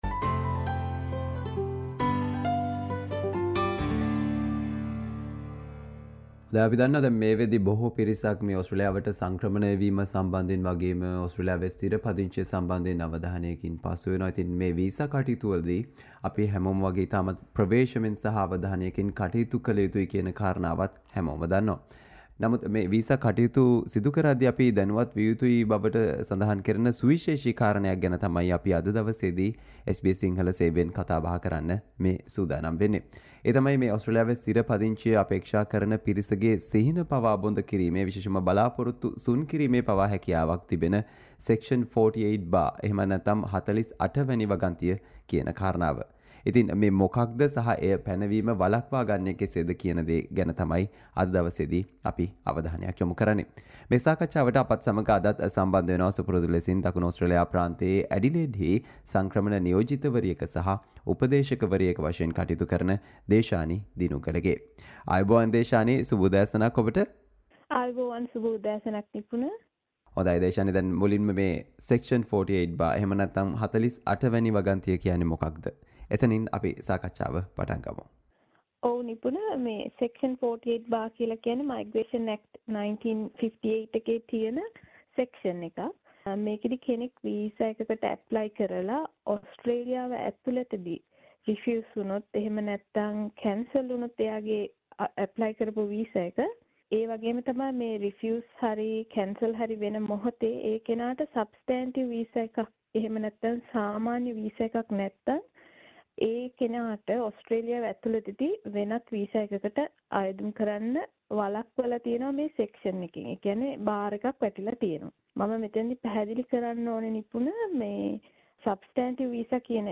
SBS Sinhala discussion on "Section 48 Bar"